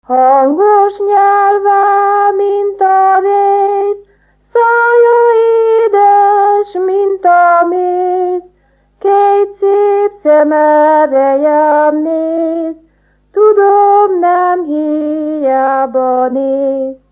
Erdély - Udvarhely vm. - Korond
ének
Műfaj: Virágének
Stílus: 7. Régies kisambitusú dallamok
Szótagszám: 7.7.4.7